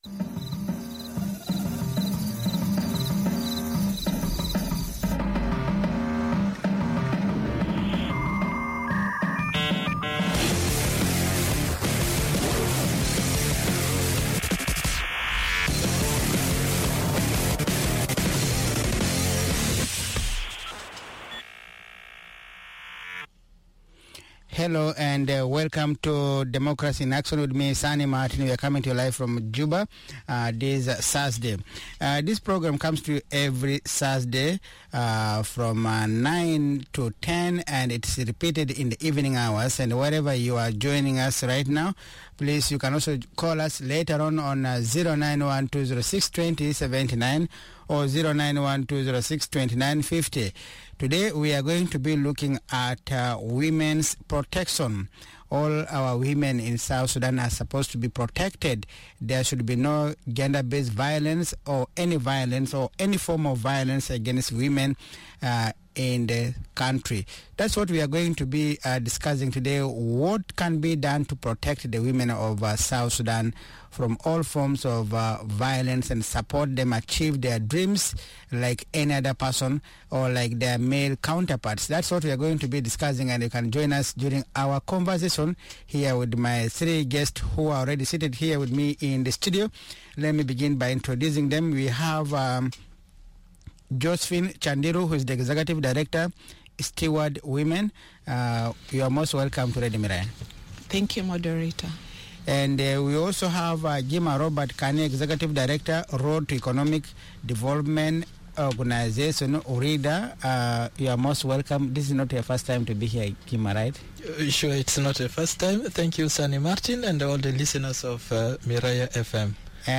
The panel discusses the various facets of protection of women ranging from conflict-related sexual violence to ensuring access to provision of access to justice for women, girls and children who are victims of gender based violence and discrimination.